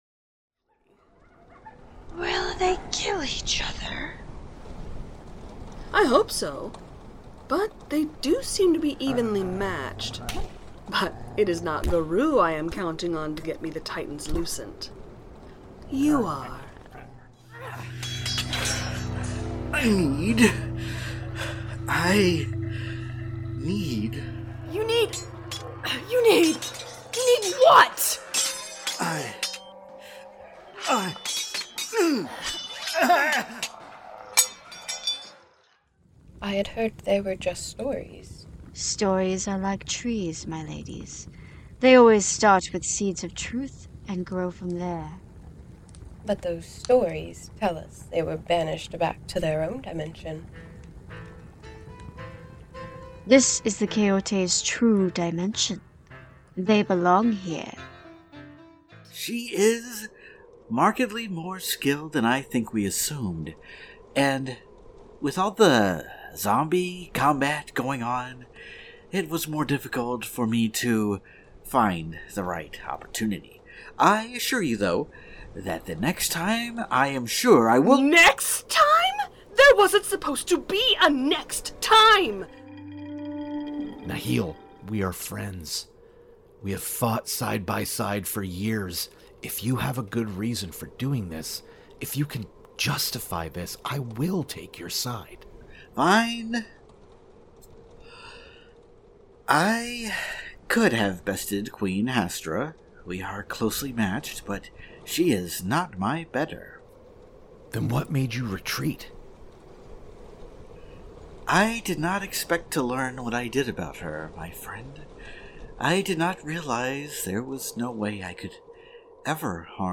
best audio drama